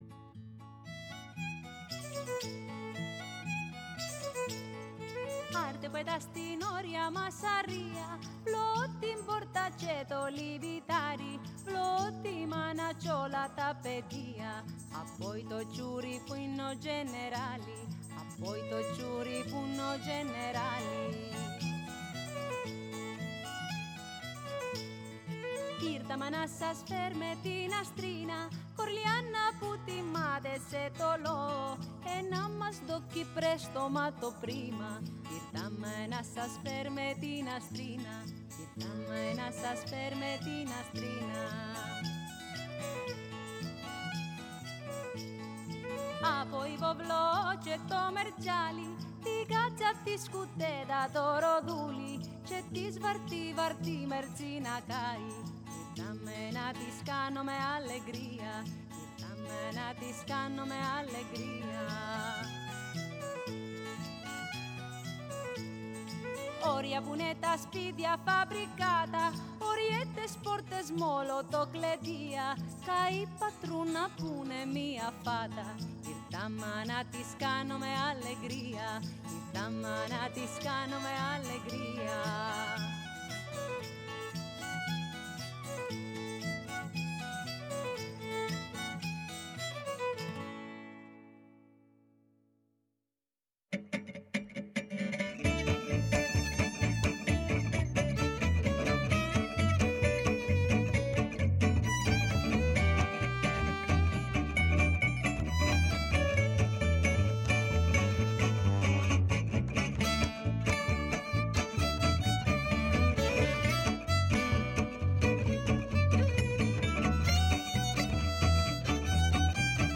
Στο στούντιο της “Φωνής της Ελλάδας”